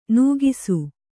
♪ nūgisu